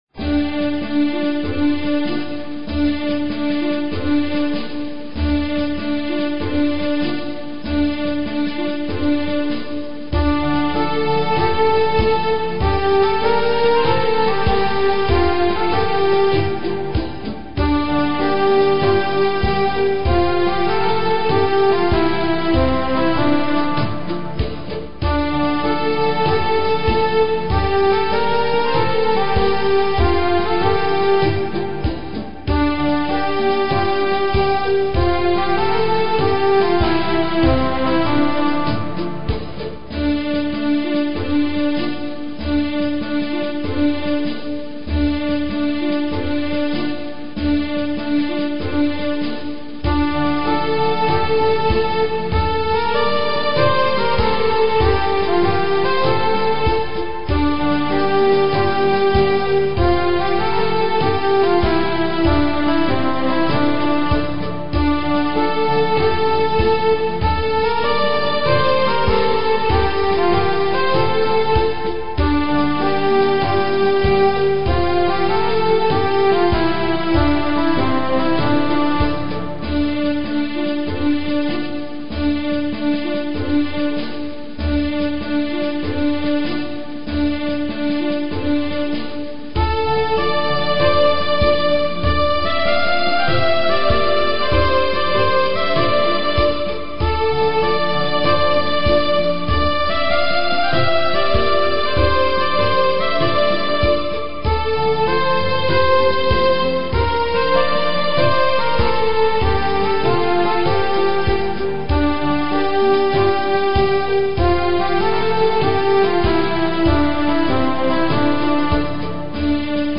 سرودهای انقلابی
بی‌کلام